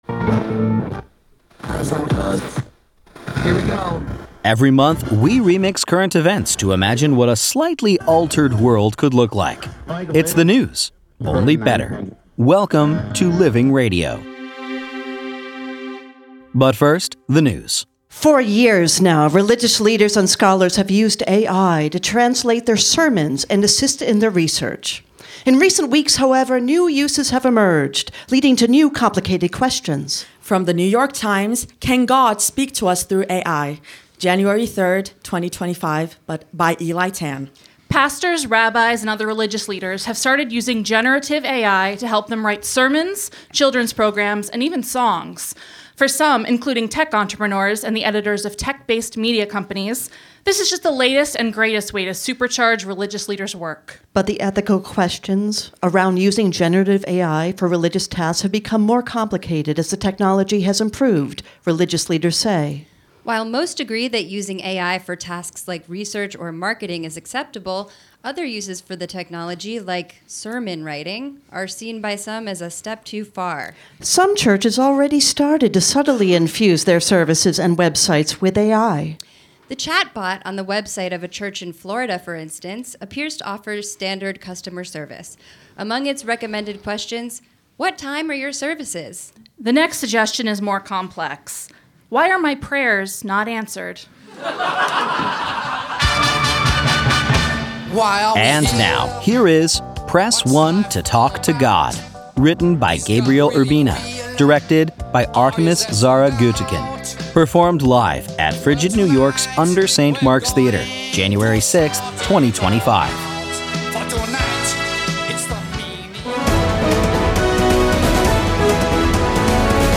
performed live at UNDER St. Mark’s Theater, January 6, 2025